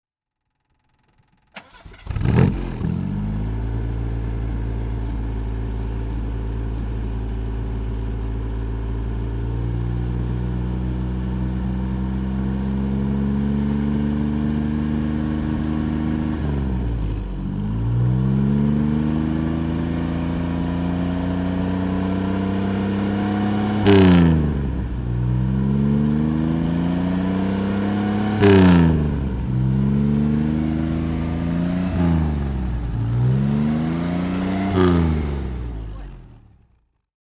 Marcos 3 Litre (1971) - die Ford-Variante hat zwei seprate Auspuffendrohre
Marcos 3 Litre (1971) - Starten und Leerlauf